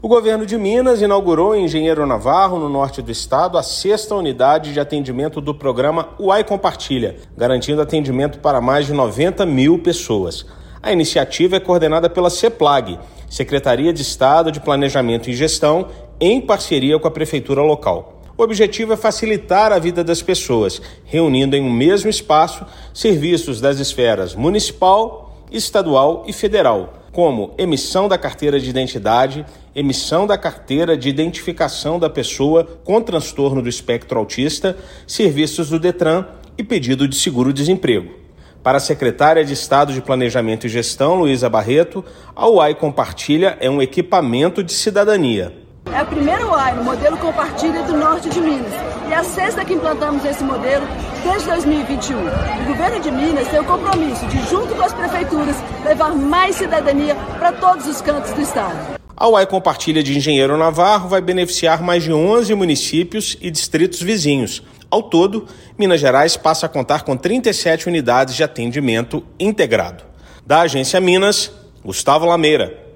O Governo de Minas inaugurou no município de Engenheiro Navarro a sexta unidade de atendimento do programa UAI Compartilha. Ouça matéria de rádio.